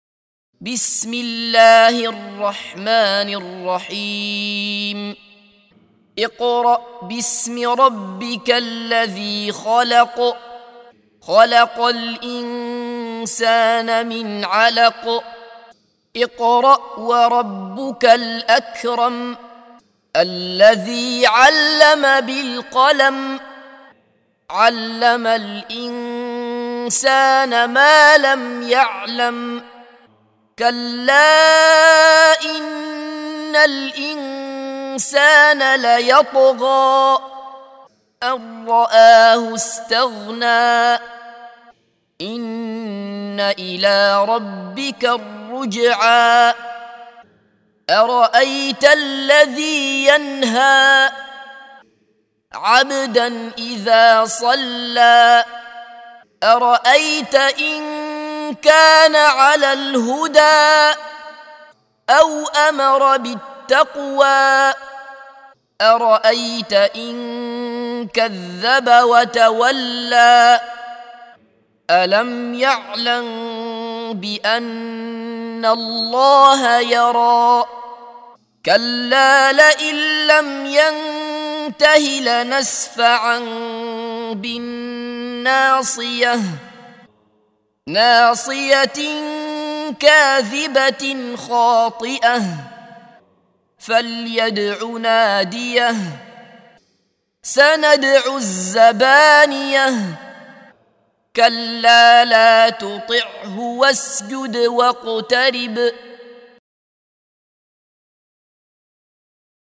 سُورَةُ العَلَقِ بصوت الشيخ عبدالله بصفر